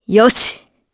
Priscilla-Vox_Happy4_jp.wav